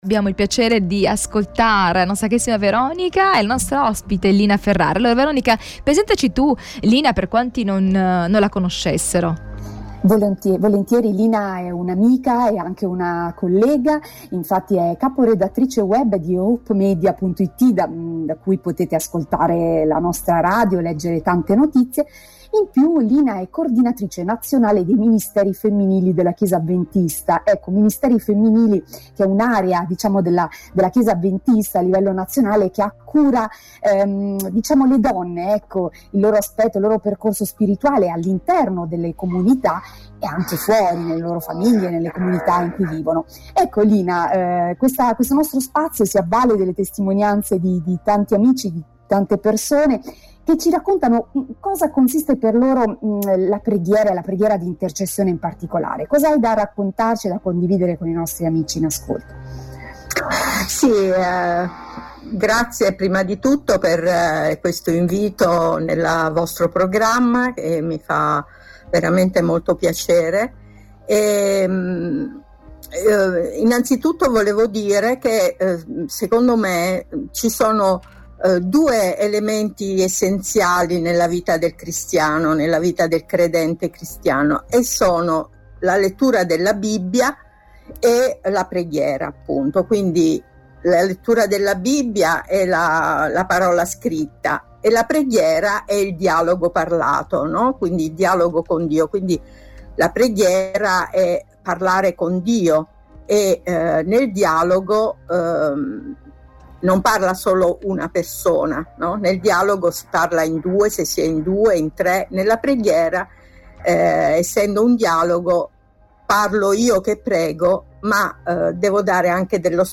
La preghiera di intercessione è un’esperienza unica e arricchente da vivere da soli ma anche in gruppo. Segui il dialogo